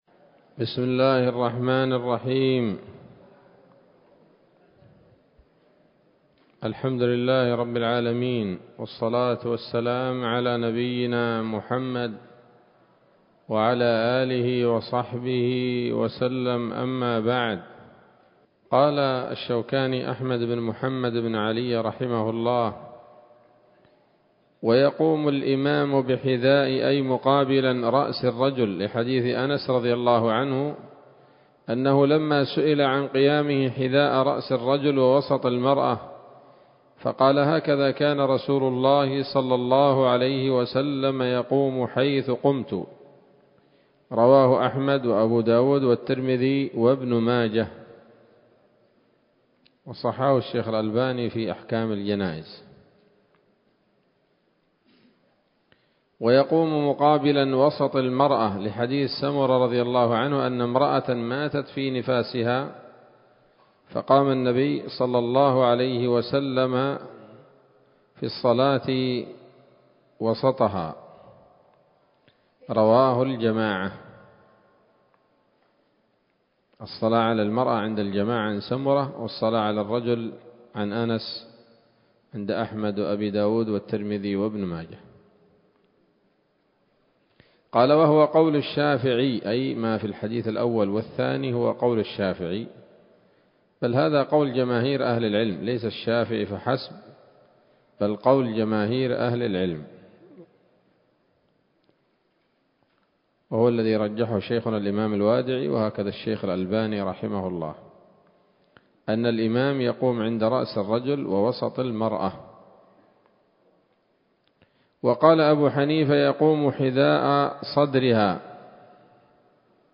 الدرس الرابع من كتاب الجنائز من السموط الذهبية الحاوية للدرر البهية